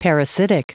Prononciation du mot parasitic en anglais (fichier audio)
Prononciation du mot : parasitic